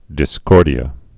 (dĭ-skôrdē-ə)